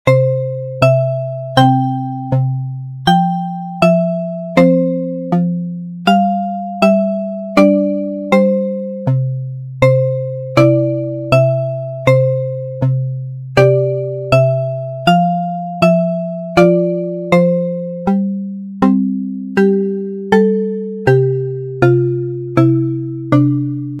日常っぽい。ループ対応。
BPM80